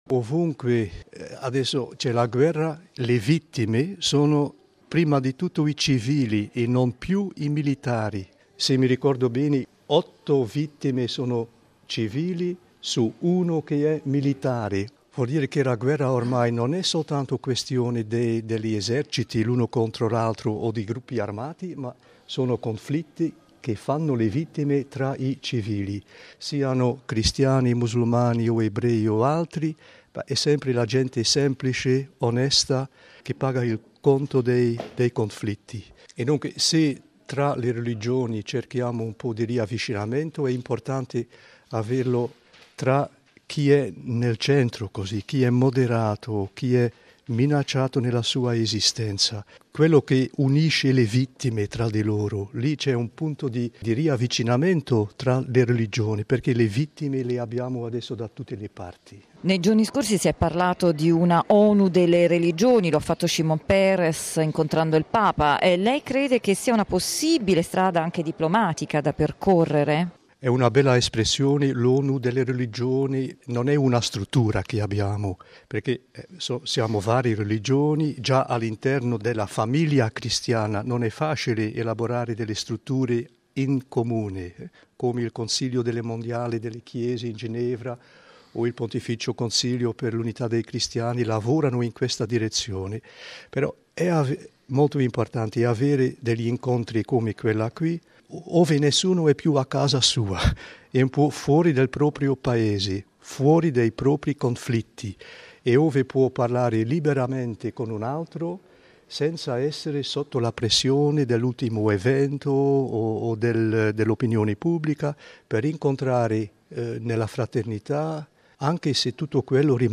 ha intervistato il vescovo di Anversa, mons. Johan Bonny